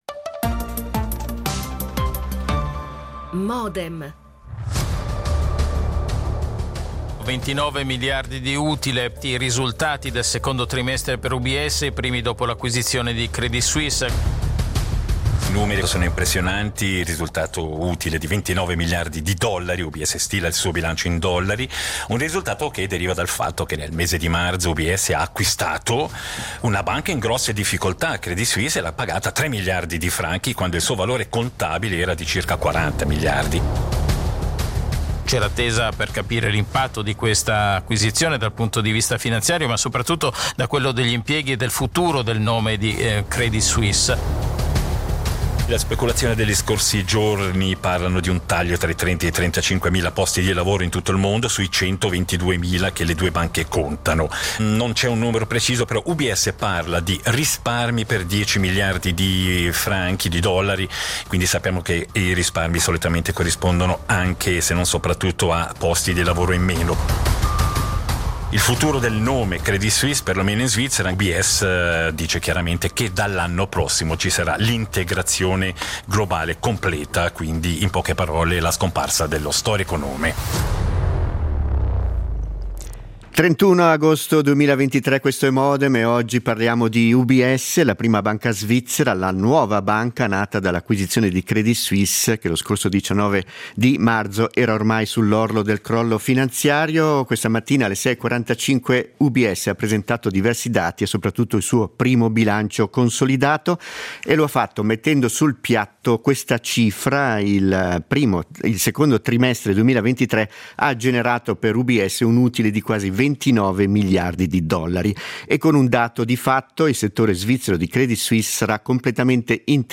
Modem RSI - l'attualità approfondita, in diretta radio e visualradio - tutte le mattine, da lunedì a venerdì a partire dalle 08:30